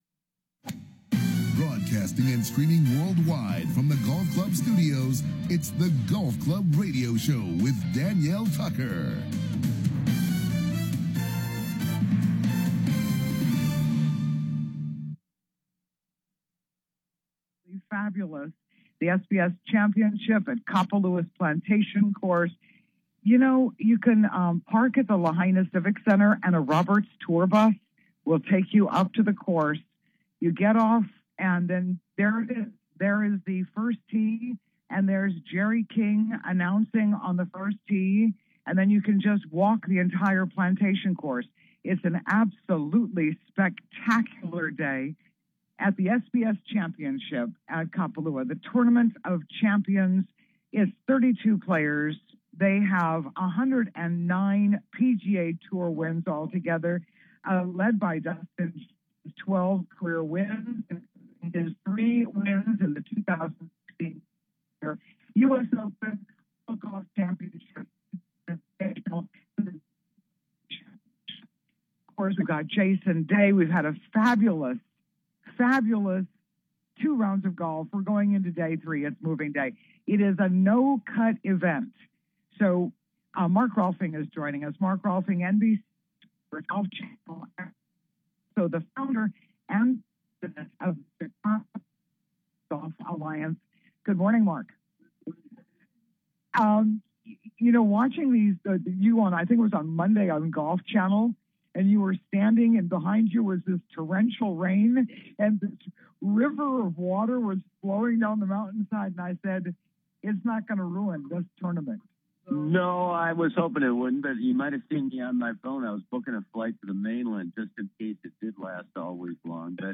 Live From Kapalua SBS Tournament of Champion